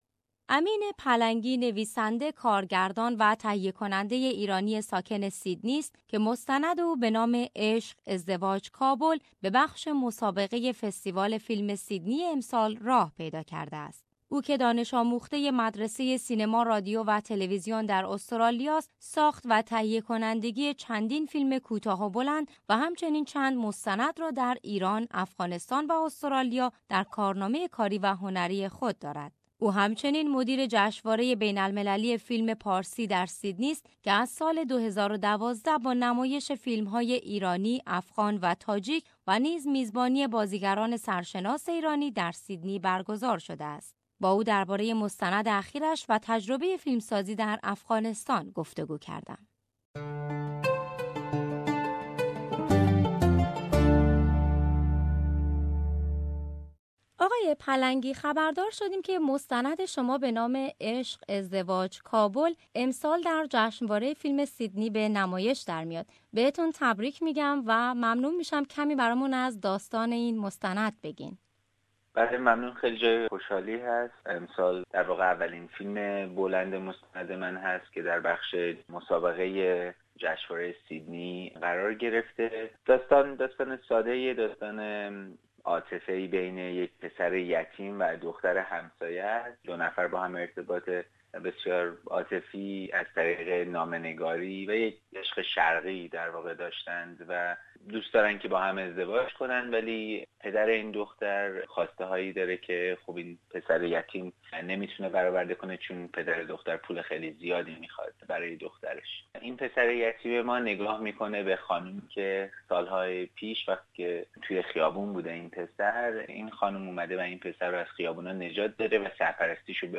با او درباره مستند اخیرش و تجربه فیلمسازی در افعانستان گفت و گو کردم